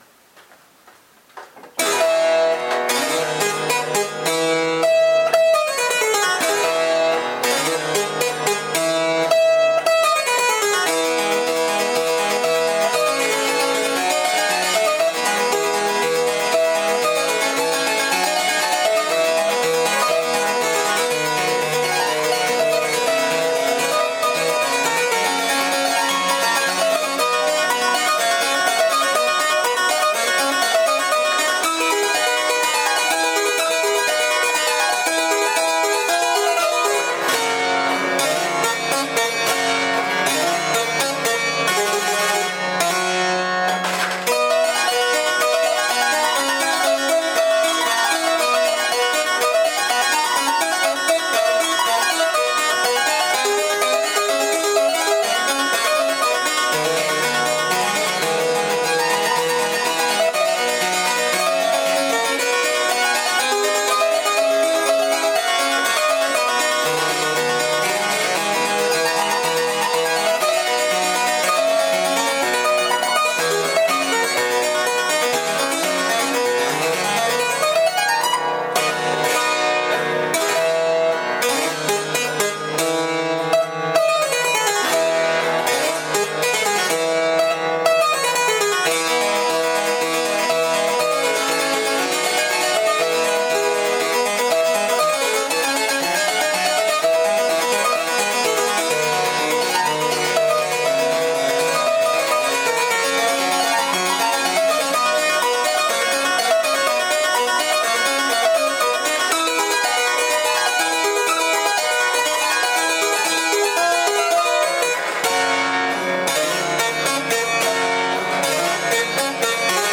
Perseguendo questo scopo il ciclo Mani sulla Tastiera del Clavicembalo continua, ed ecco che alla registrazione audio, affiancheremo le riprese dinamiche dell'esecuzione.